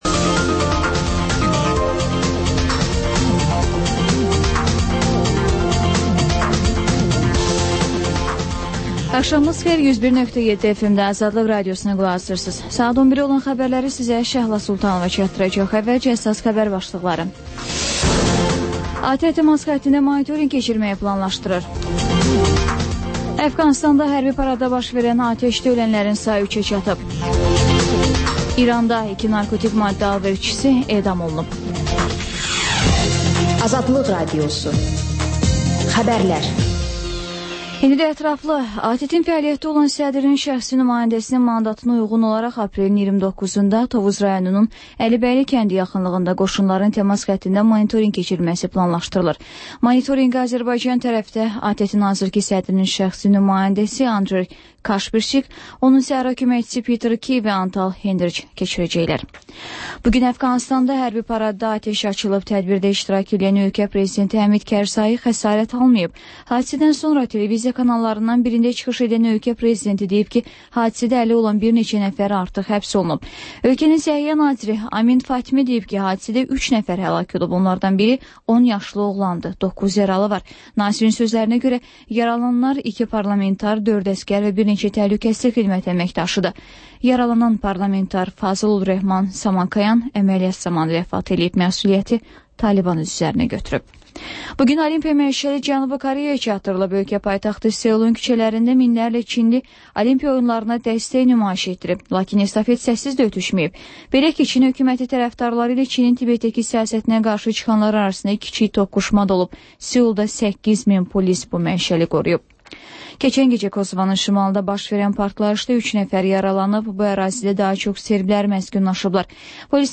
Xəbərlər, RAP-TIME: Gənclərin musiqi verilişi